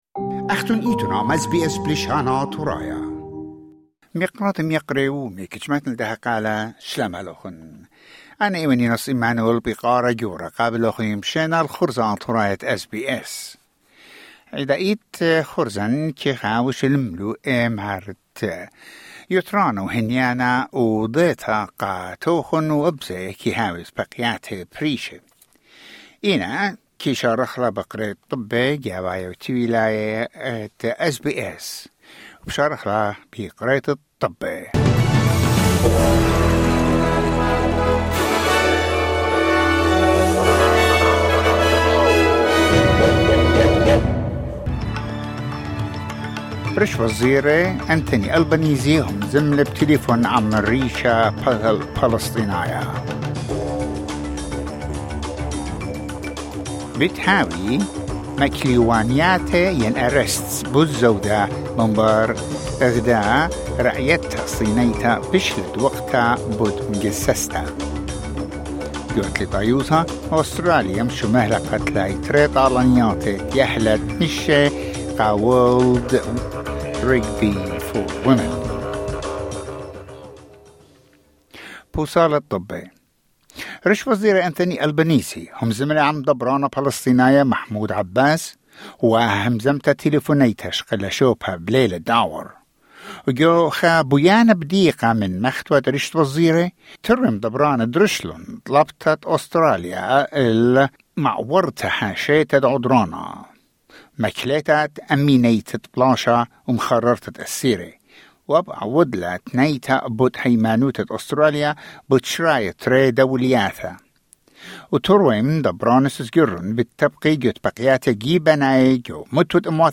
News bulletin: 5 August 2025